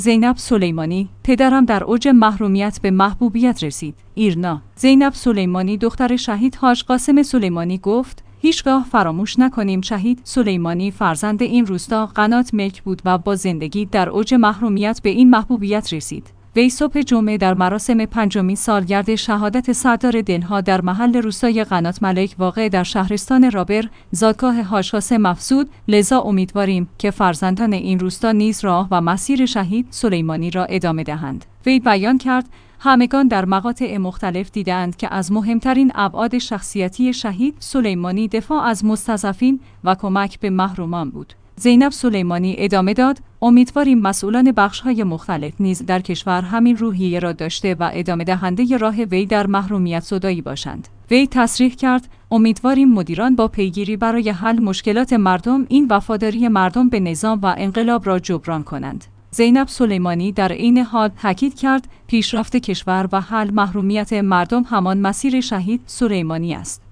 وی صبح جمعه در مراسم پنجمین سالگرد شهادت سردار دل ها در محل روستای قنات ملک واقع در شهرستان رابر، زادگاه حاج قاسم افزود: لذا امیدواریم که